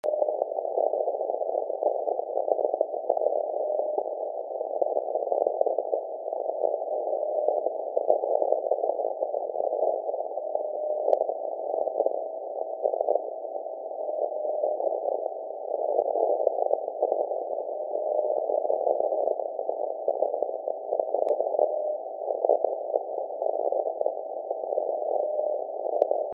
[Lowfer] first alpha 'beeps' of the season
>  From last night ... 11904 kHz.
> modified Amrad e probe>LNA>Delta 44>SpectrumLab